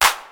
Clap (4).wav